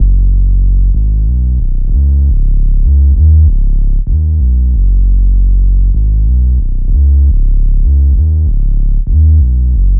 FK096BASS1-R.wav